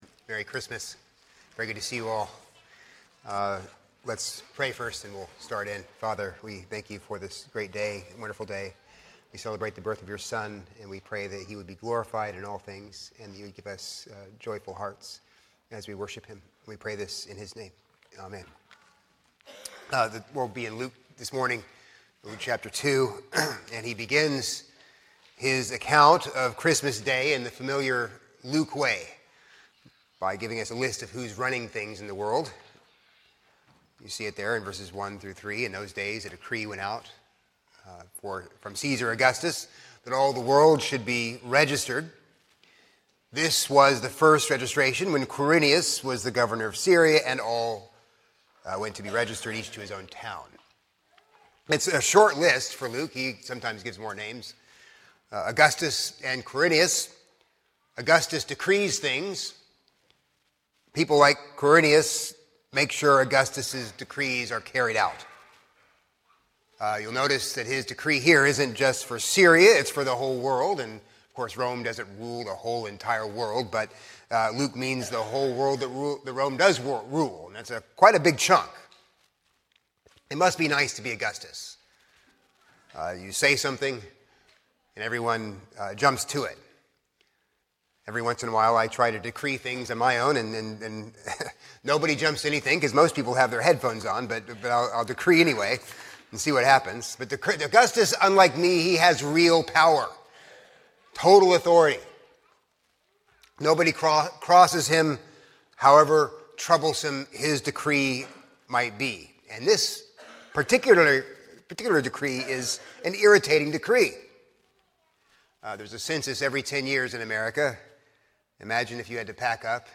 A sermon on Luke 2:1-17